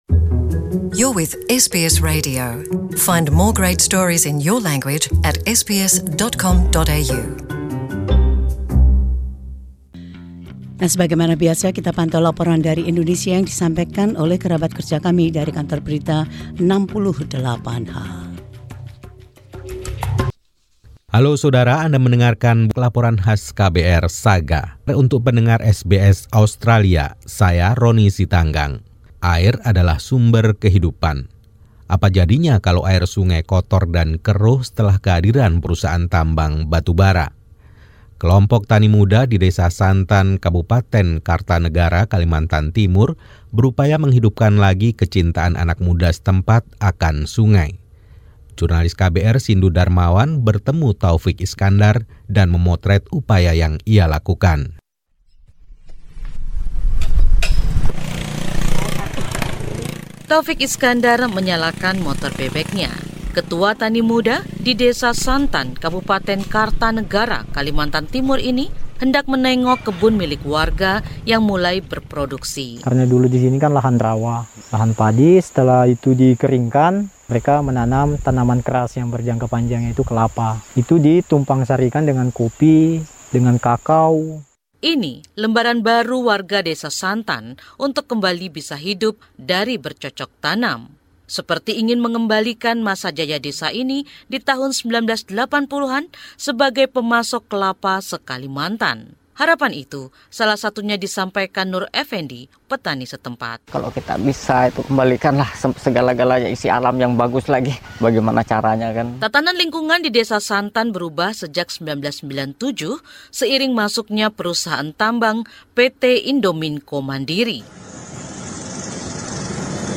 Mengapa penduduk lokal mentgeluh tentang dampak penambangan terhadap kehidupan sungai itu? Bagaimana penduduk lokal mengambil kembali Sungai Santan? Tim KBR 68H melaporkan.